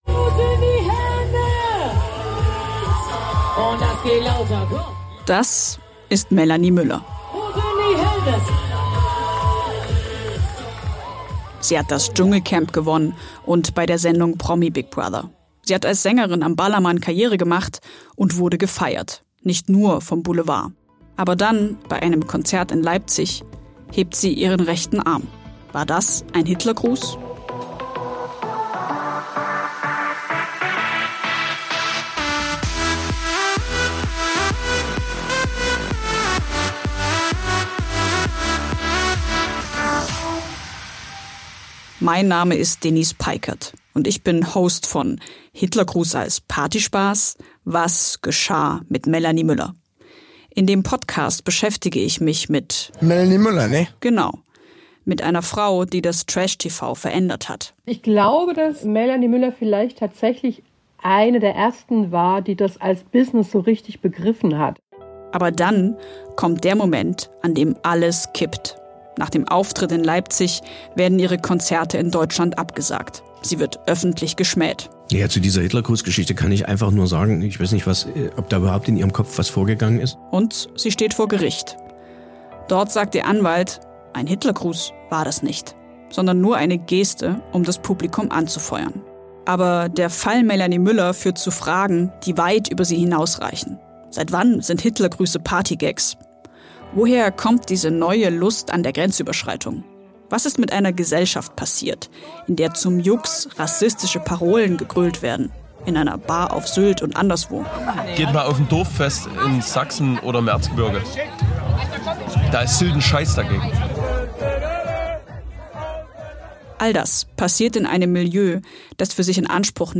Musik im Trailer